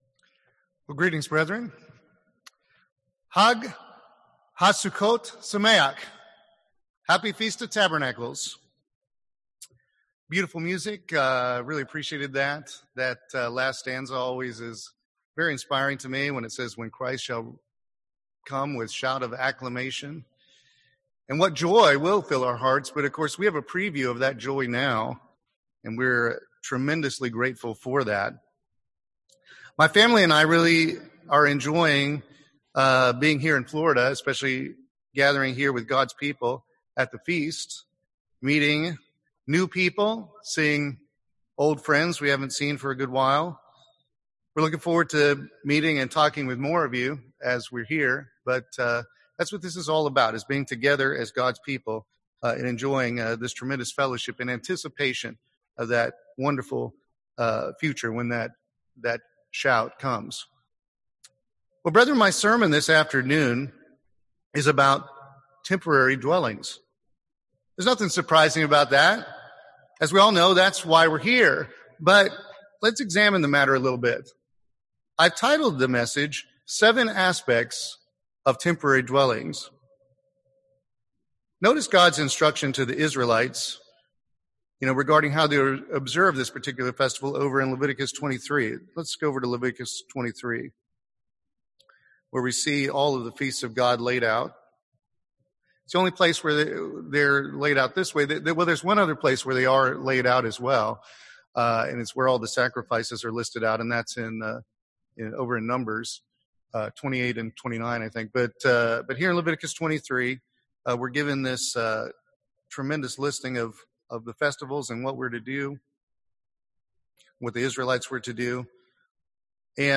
This sermon was given at the Daytona Beach, Florida 2020 Feast site.